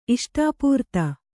♪ iṣṭāpūrta